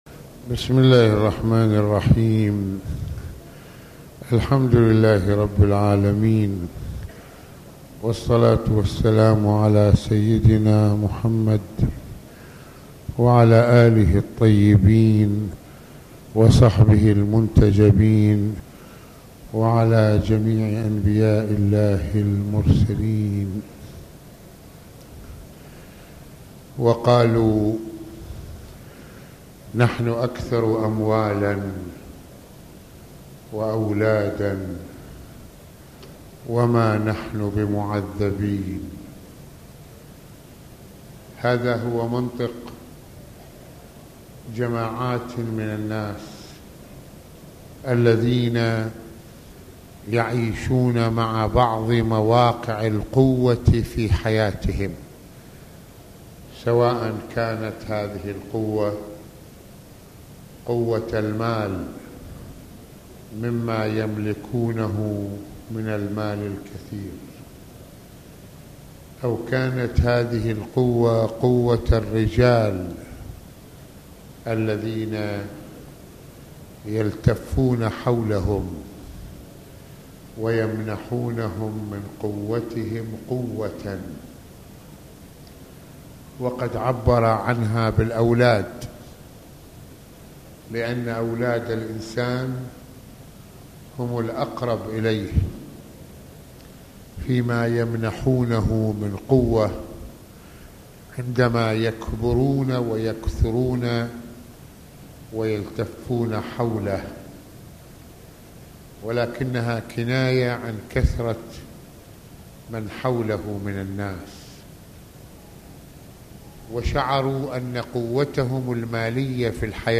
- المناسبة : موعظة ليلة الجمعة المكان : مسجد الإمامين الحسنين (ع) المدة : 36د | 32ث المواضيع : المال والبنون والحكمة في التوزيع الالهي للنعم - الانفاق في سبيل الله - مشاهد من يوم القيامة - دفاع ابليس عن نفسه في القيامة.